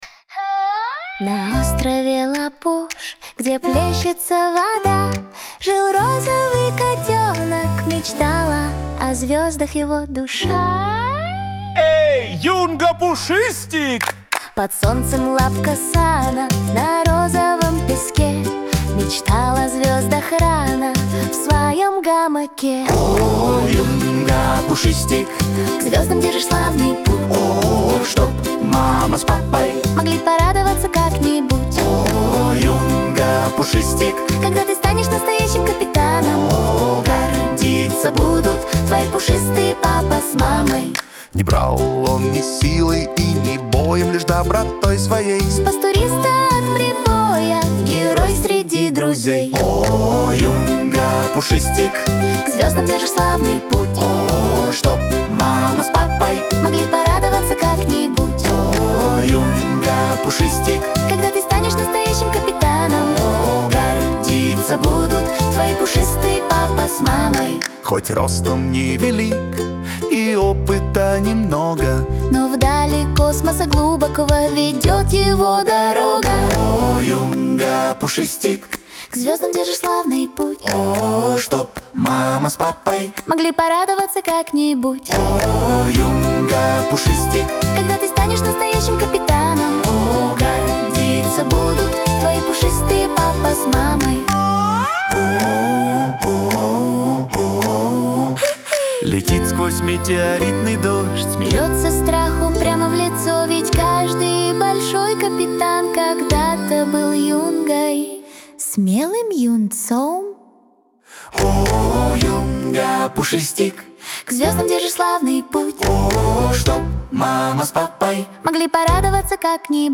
фермерская версия). Саундтрек